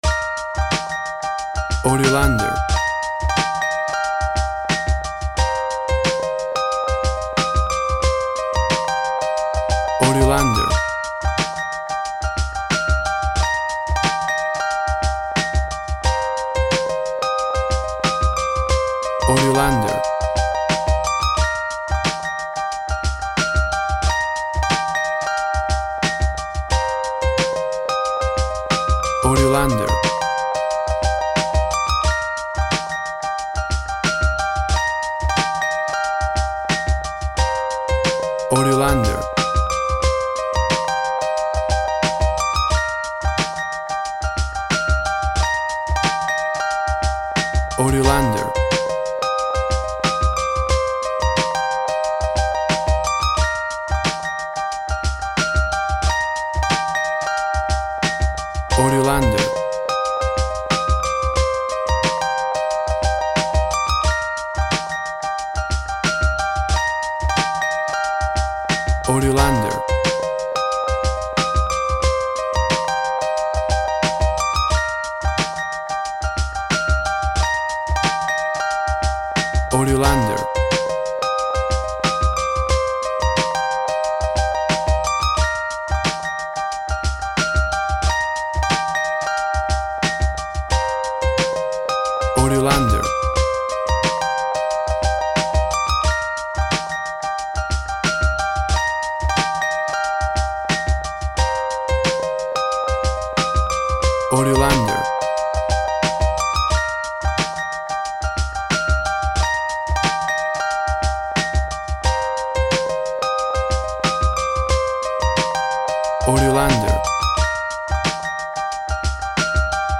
Old school hip hop east coast sound.
Tempo (BPM) 100